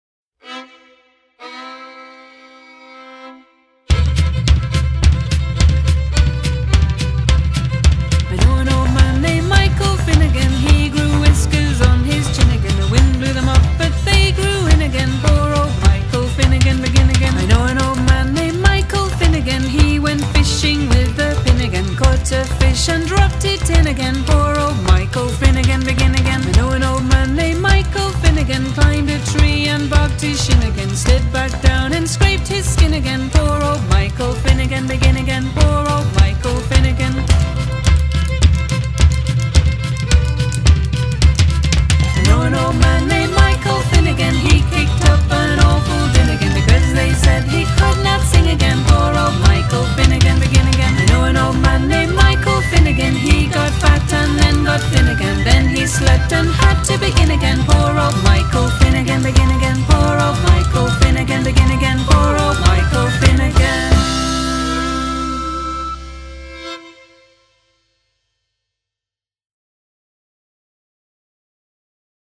TraditionalFolk